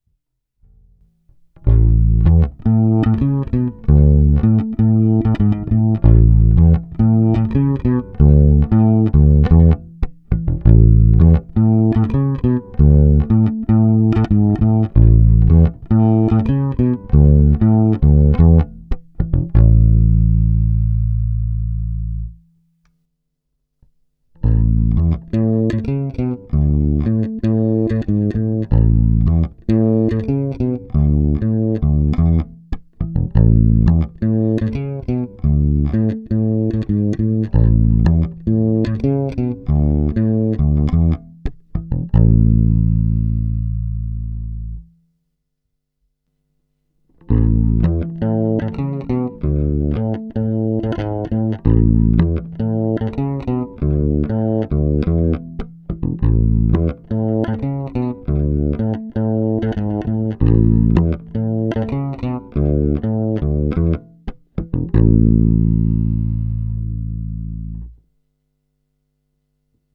Nahrál jsem několik ukázek v pořadí krkový snímač, oba snímače, kobylkový snímač. Použité struny jsou notně hrané nylonové tapewound hlazenky Fender 9120. Nahráno je to vždy přímo do zvukové karty a nahrávky byly jen normalizovány, jinak ponechány bez dodatečných úprav.
Pasívní režim